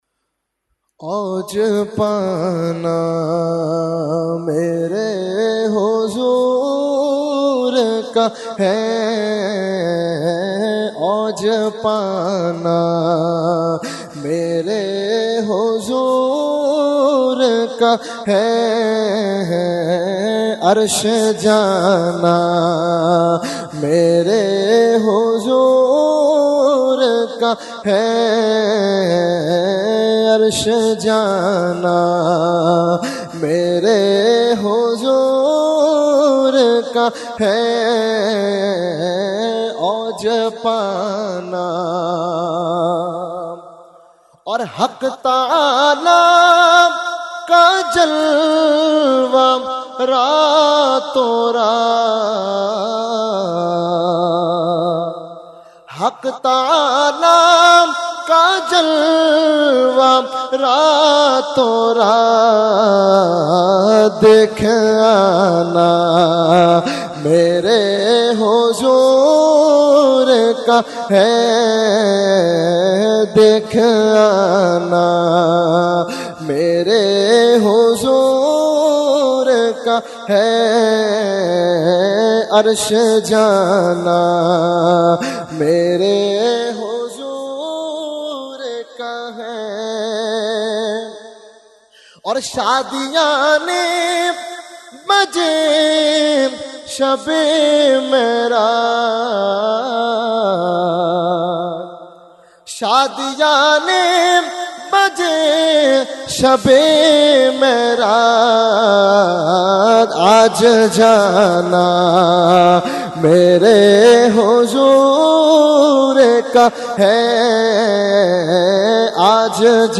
Category : Naat | Language : UrduEvent : Shab e Meraj 2019